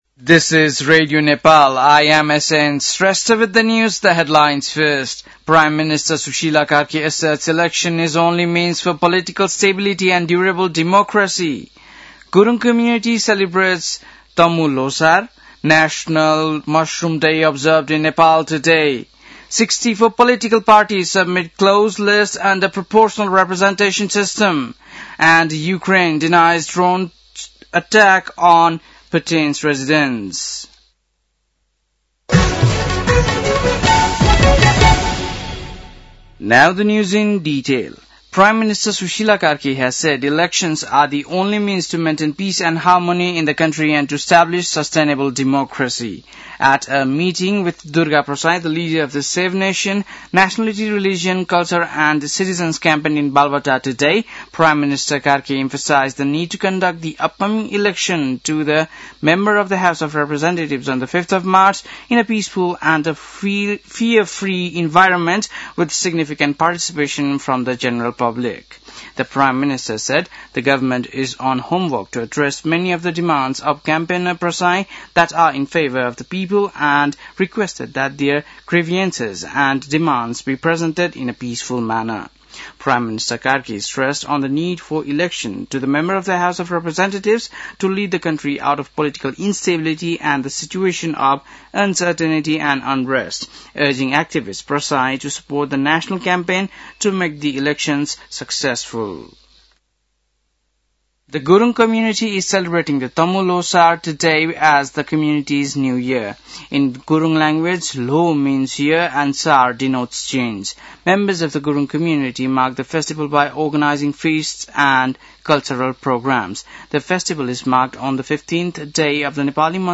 बेलुकी ८ बजेको अङ्ग्रेजी समाचार : १५ पुष , २०८२